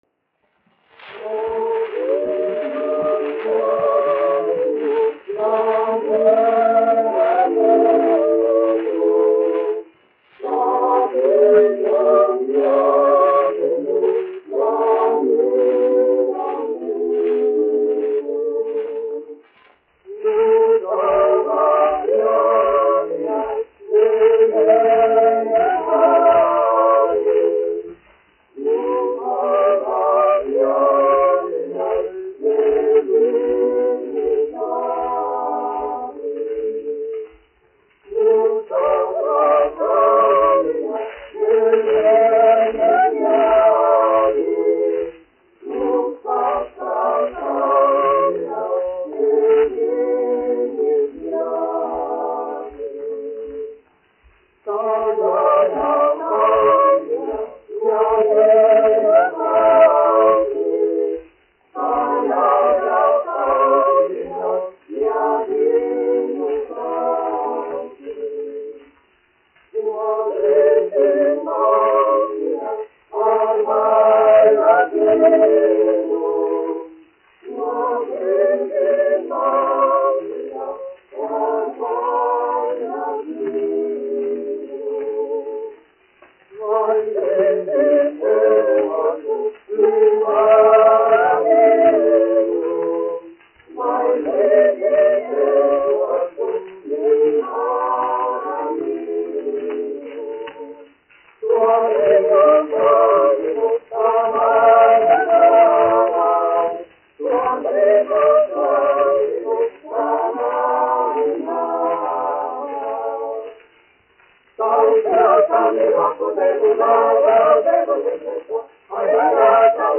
1 skpl. : analogs, 78 apgr/min, mono ; 25 cm
Latviešu tautasdziesmas
Kori (jauktie)
Skaņuplate
Latvijas vēsturiskie šellaka skaņuplašu ieraksti (Kolekcija)